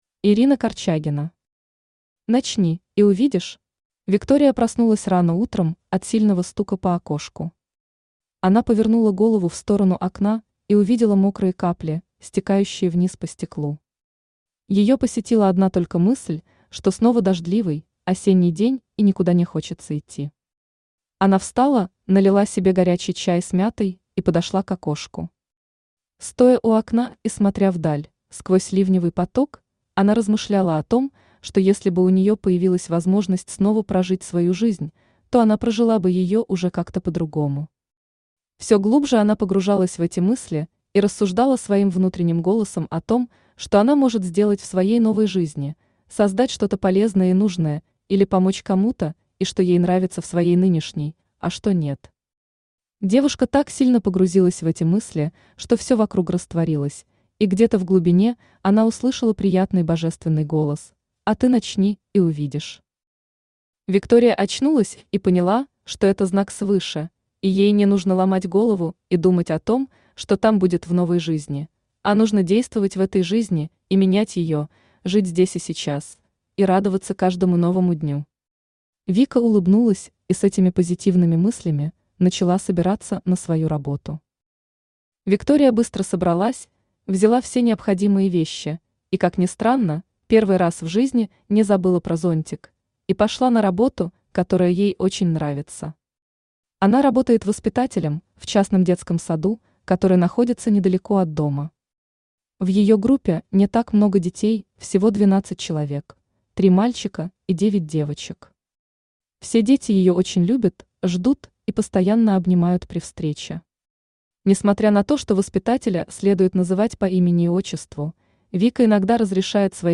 Aудиокнига Начни, и увидишь Автор Ирина Юрьевна Корчагина Читает аудиокнигу Авточтец ЛитРес.